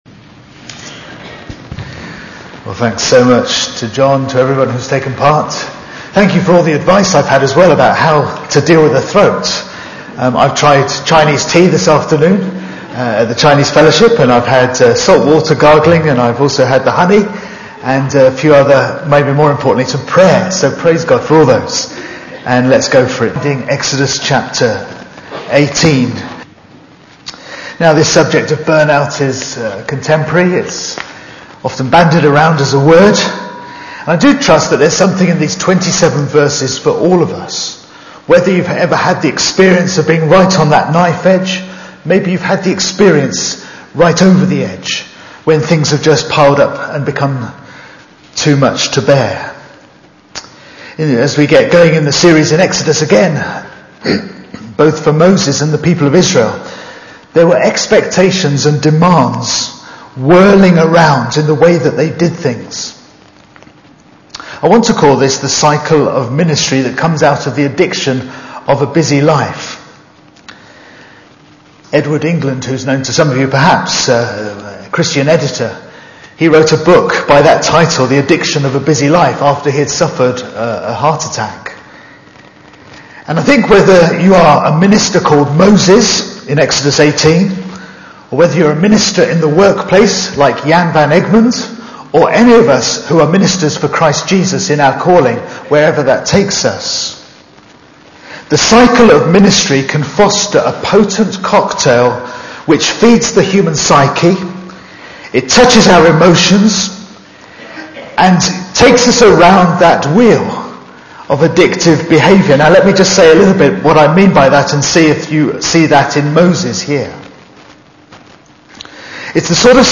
Beating Burnout - Cambray Baptist Church